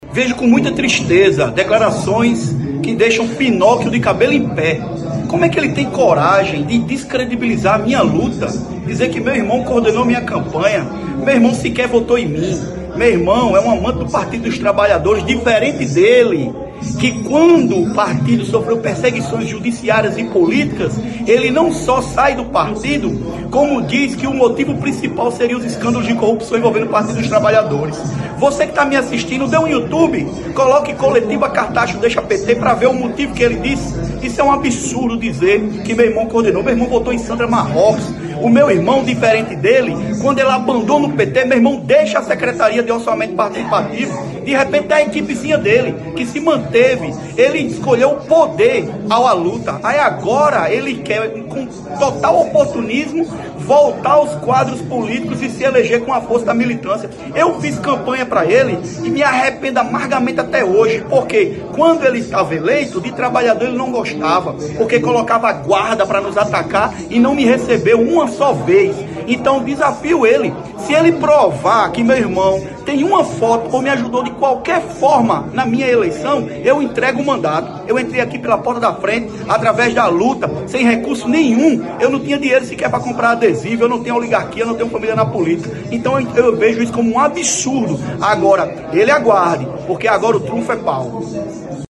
O comentário do vereador foi registrado pelo programa Correio Debate, da 98 FM, de João Pessoa, nesta terça-feira (28/11).